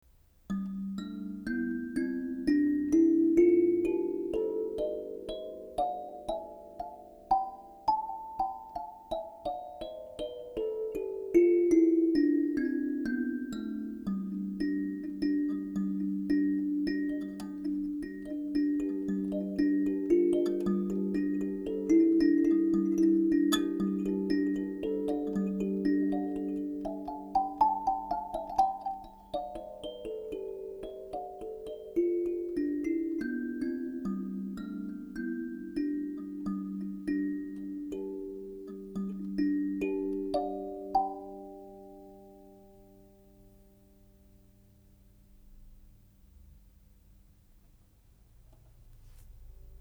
ダブルキーカリンバ
この楽器は一つの音に二つのキーを使った、非常に贅沢な作りです。その効果は、音の伸びが長くなる事、そして、 わずかの音の違いから来るコーラス的な音色になる事です。キーが三つのグループに分かれていますが、左右がピアノの白鍵、中央が黒鍵の音に対応しています。